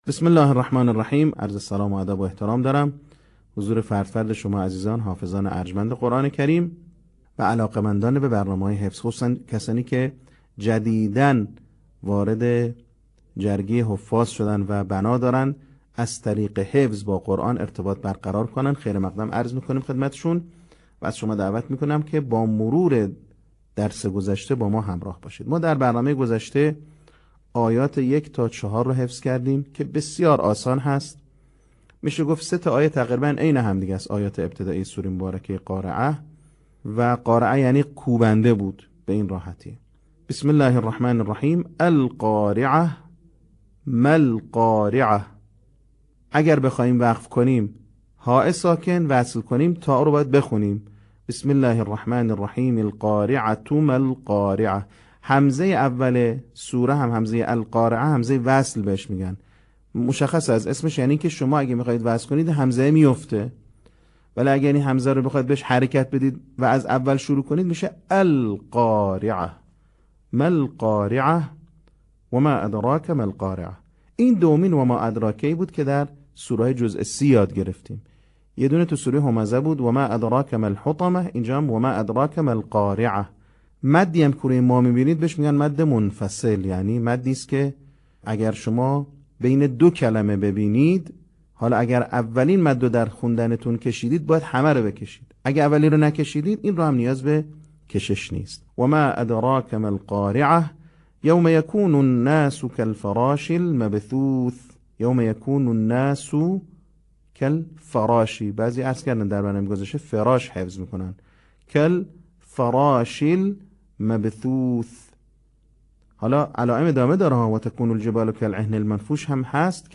صوت | بخش دوم آموزش حفظ سوره قارعه